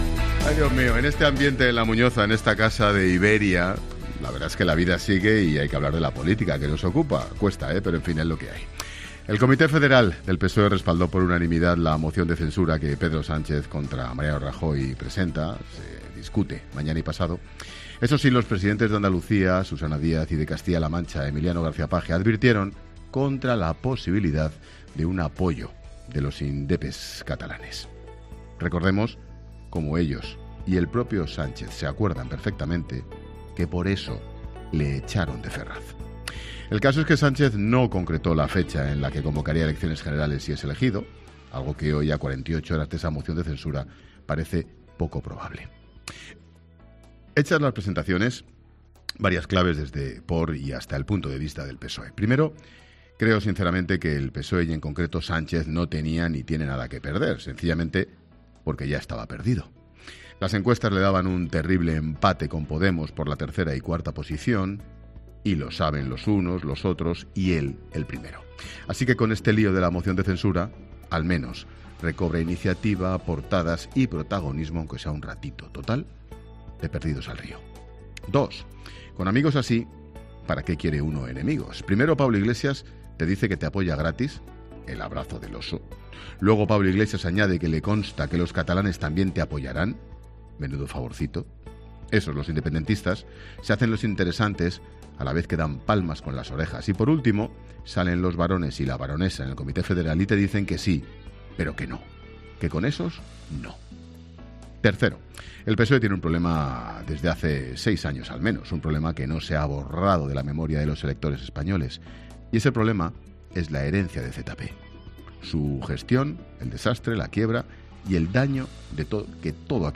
Monólogo de Expósito
Escucha el monólogo del presentador de 'La Tarde'